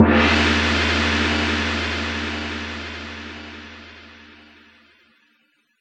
Percs
JJPercussion (93).wav